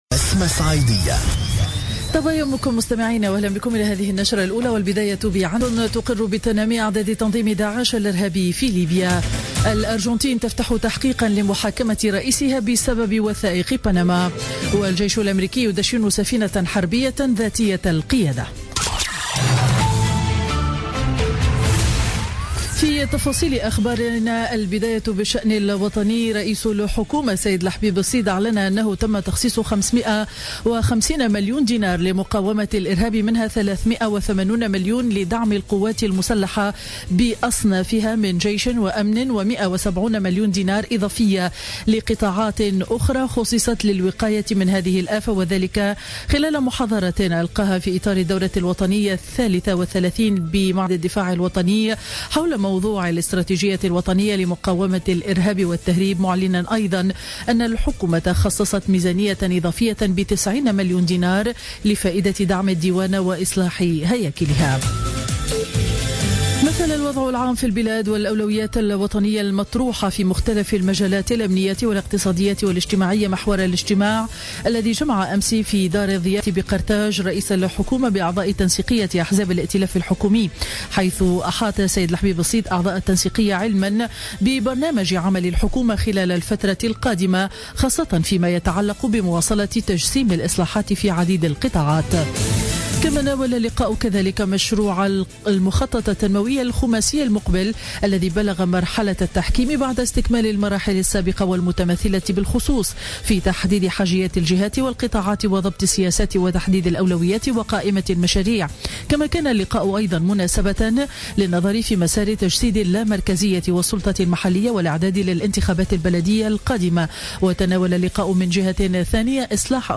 نشرة أخبار السابعة صباحا ليوم الجمعة 8 أفريل 2016